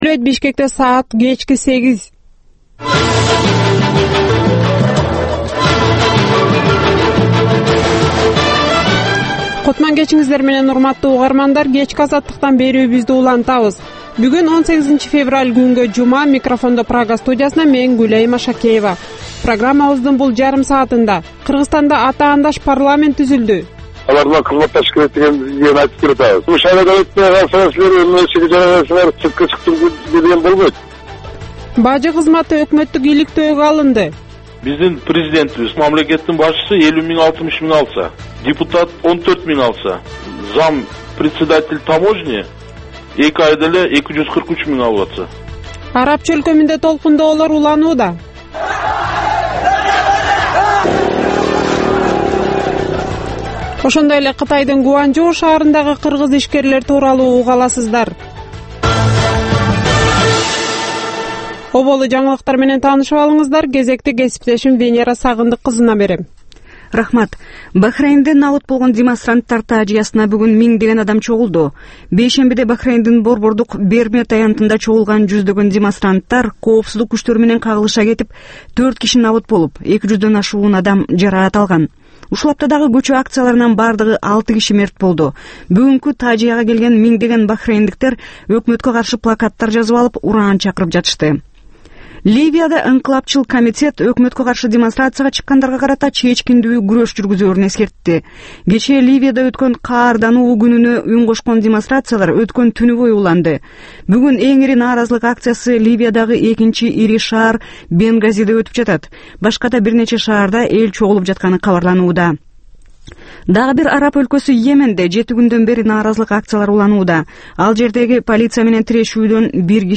Кечки 8деги кабарлар